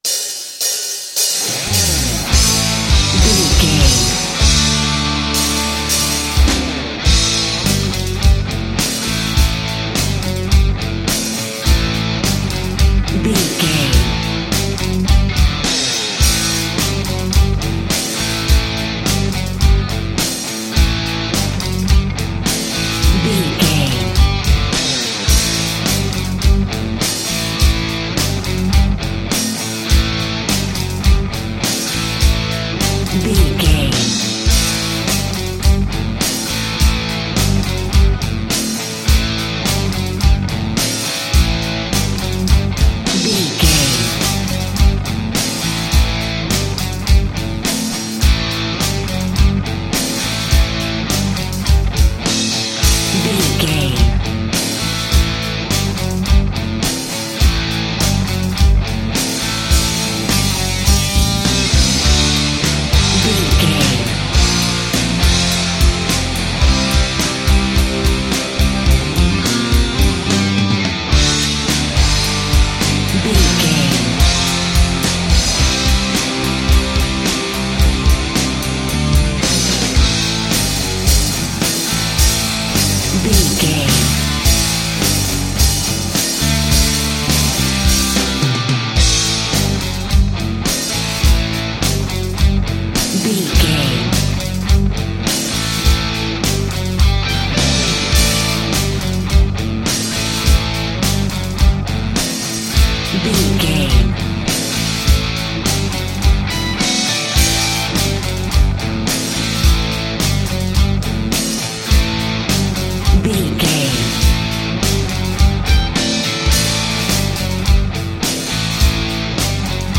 Ionian/Major
drums
electric guitar
bass guitar
hard rock
aggressive
energetic
intense
nu metal
alternative metal